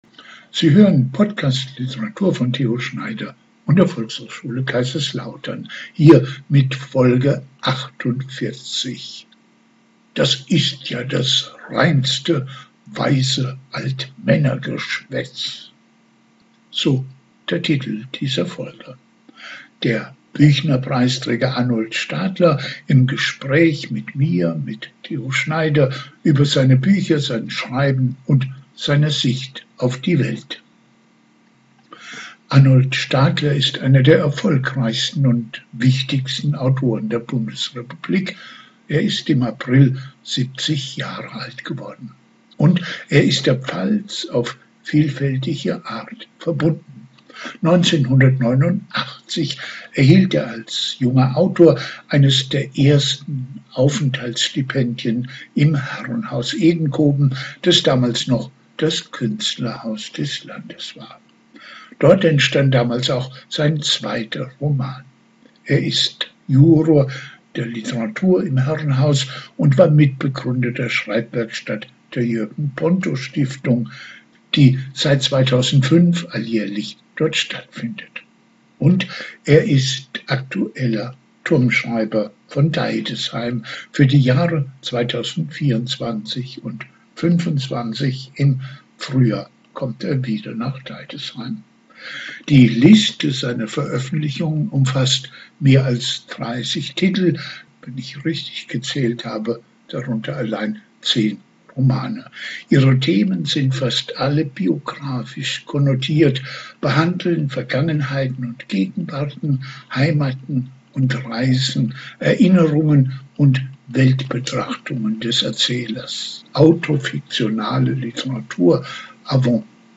Der Büchnerpreisträger Arnold Stadler im Gespräch